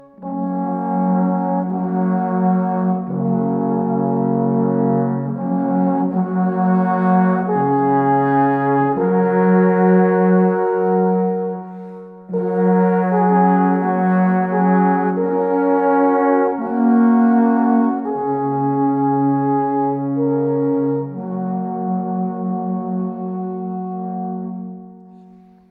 Alphorn-Trio